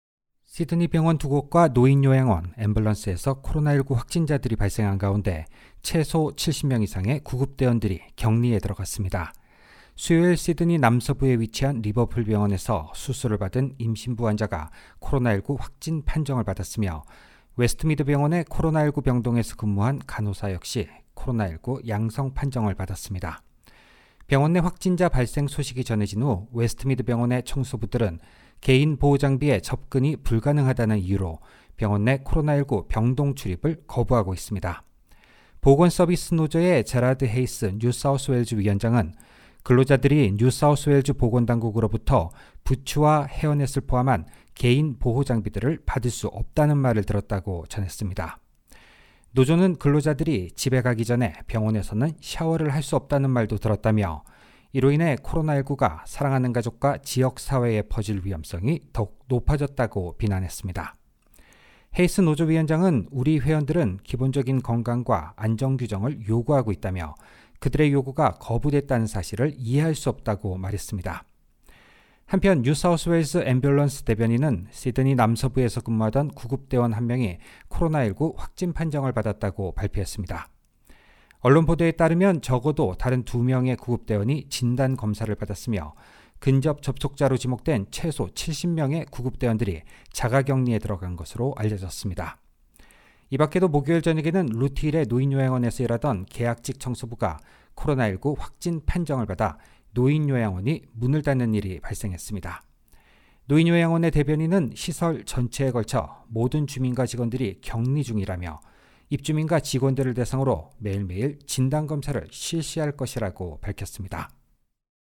1607_morning_audio_news_nsw.mp3